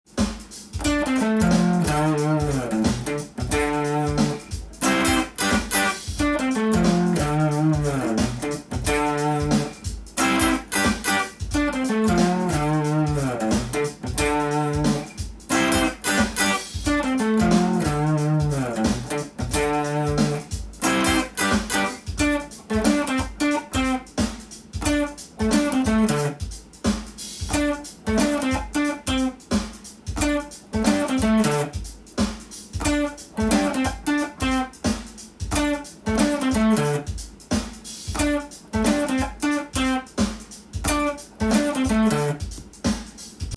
keyD　ギターtab譜